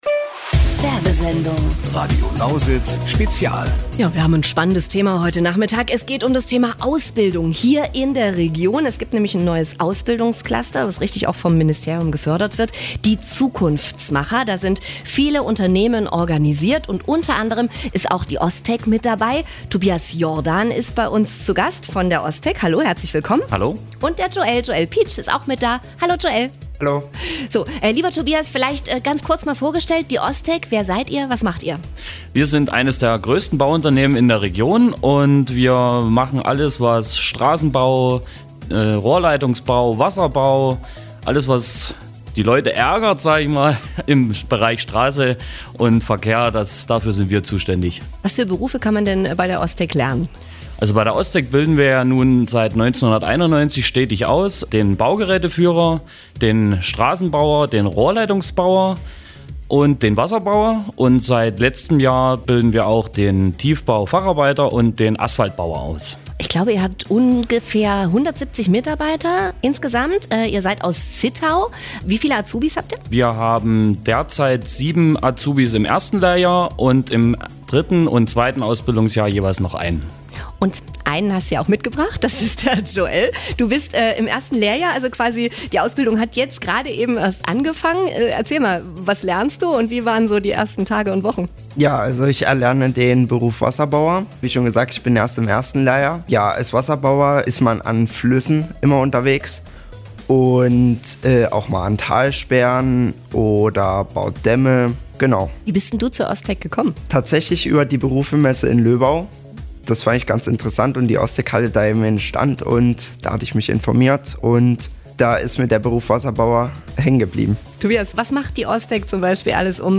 Das Ausbildungscluster Zukunftsmacher war am 26. September zu Gast in einer Sondersendung bei Radio Lausitz.